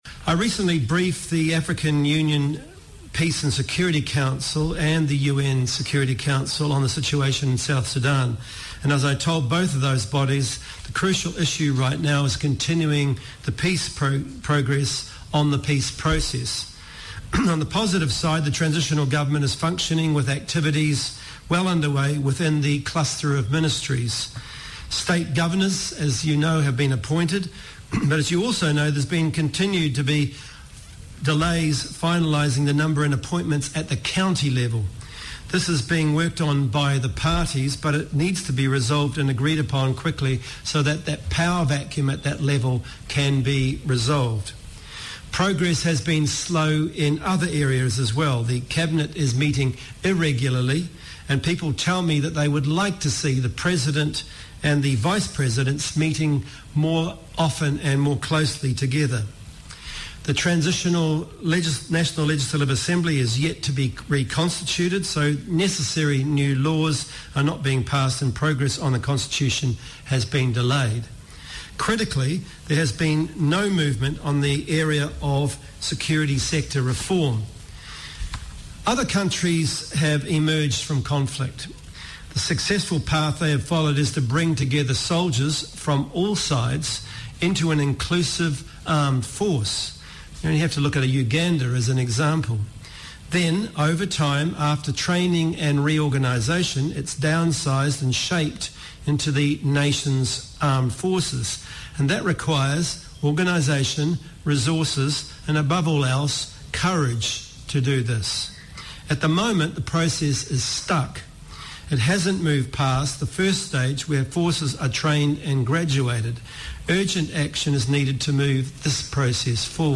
He said this at a press conference in Juba today